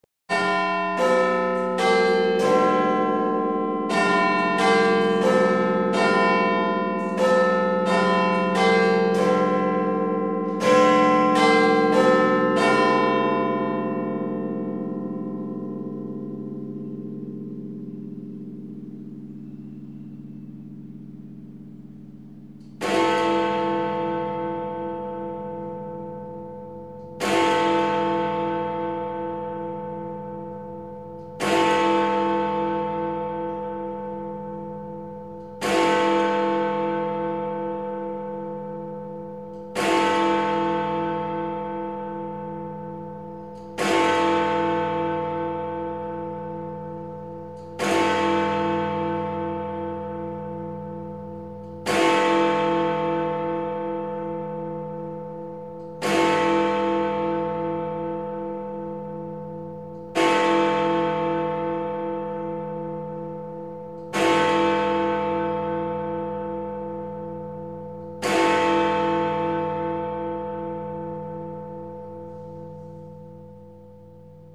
Биг-Бен - 12 часов
Отличного качества, без посторонних шумов.
250_big-ben.mp3